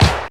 CRACK CLAP.wav